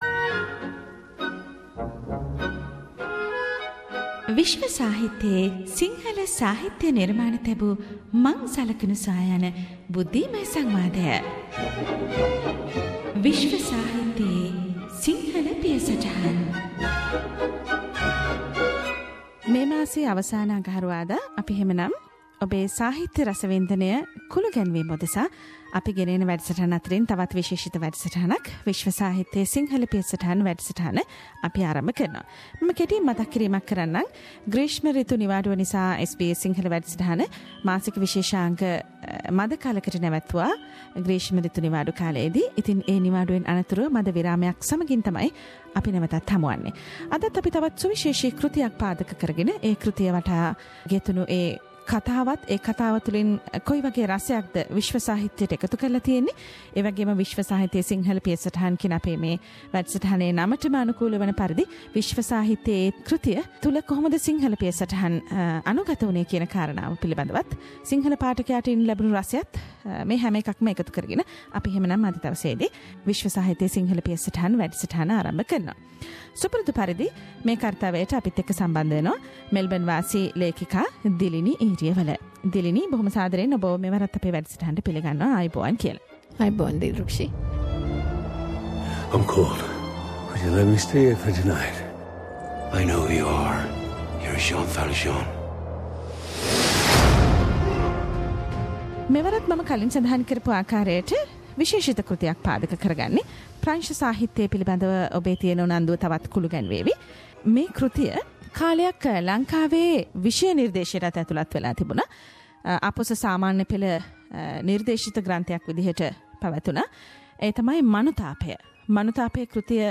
World literary discussion